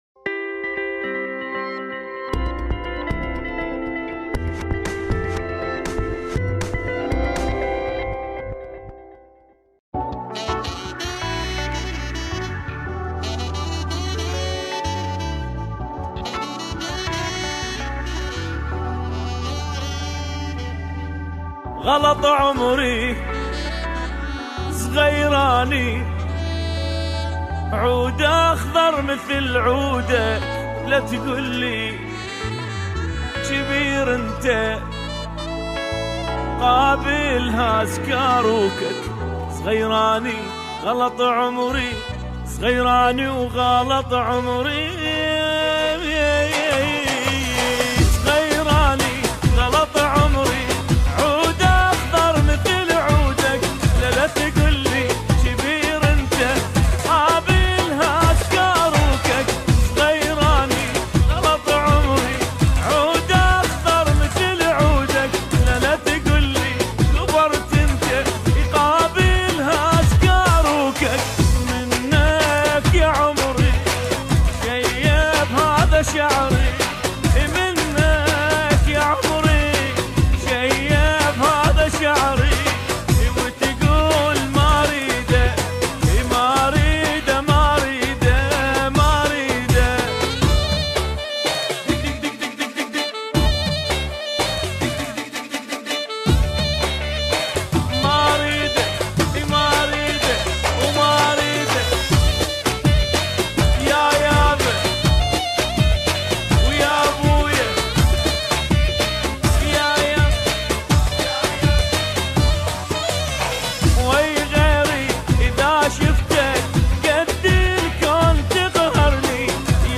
تتميز هذه الاغنية بإيقاعهاالراقص